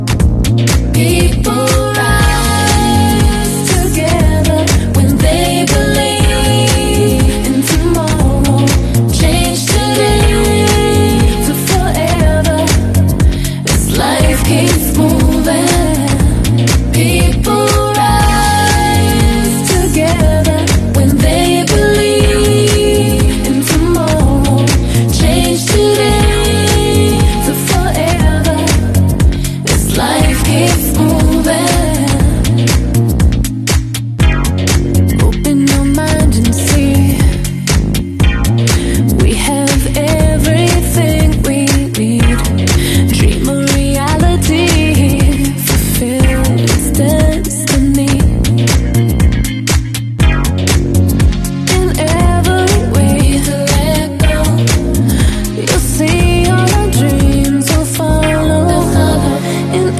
Women's Day Event With Dj Sound Effects Free Download